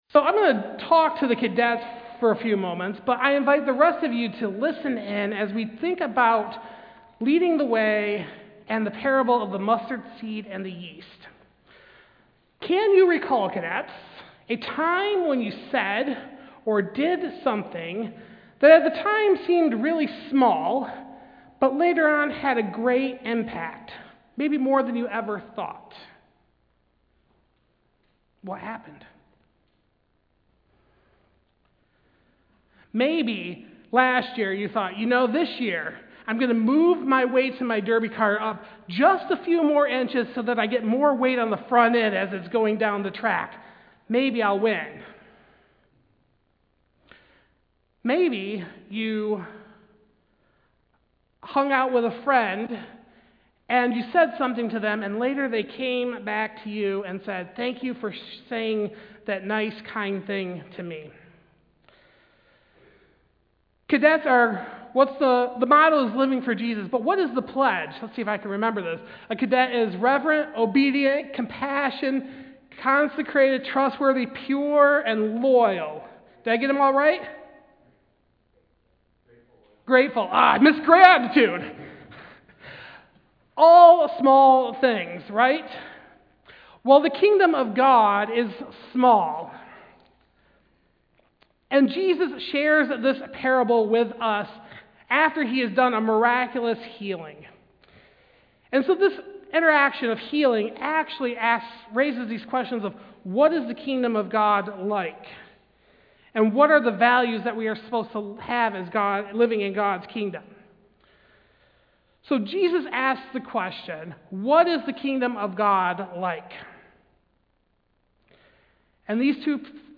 Passage: Luke 13:18-21 Service Type: Sunday Service